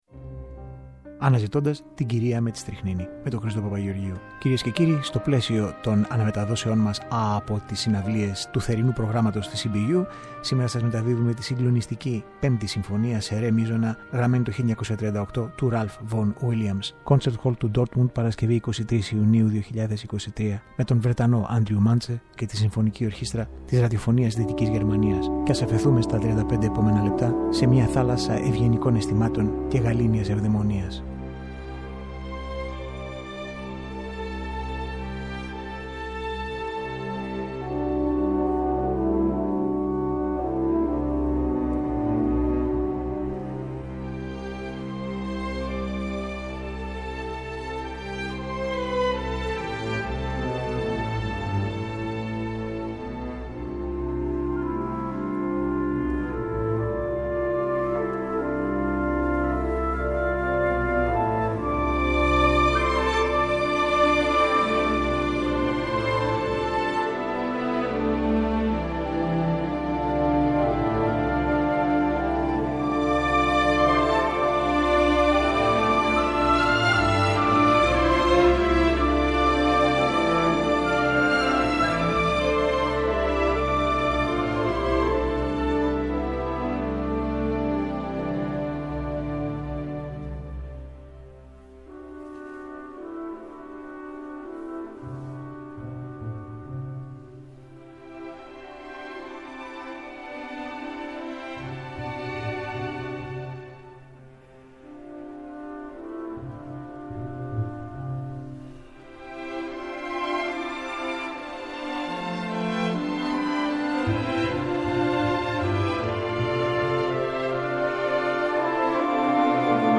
Από το Concert Hall Dortmund
Από το Παλάτι του Καρόλου του 5ου στην Αλάμπρα της Γρανάδα